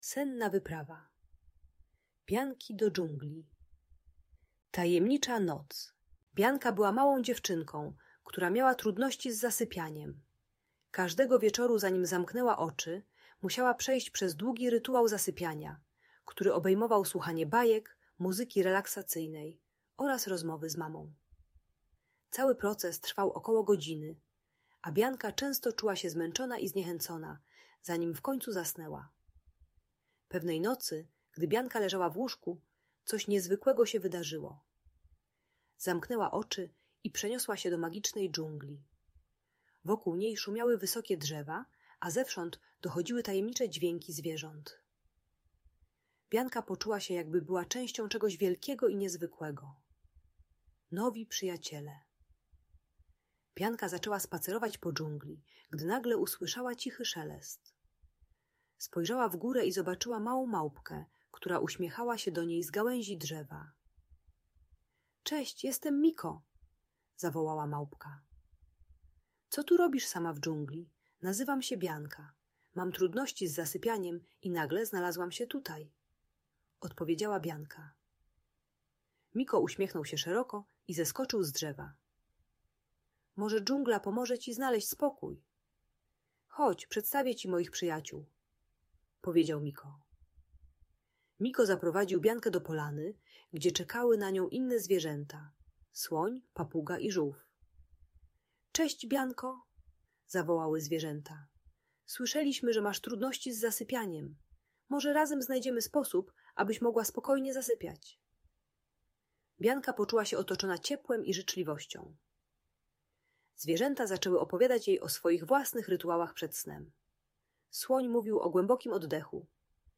Senna Wyprawa Bianki do Dżungli - Audiobajka dla dzieci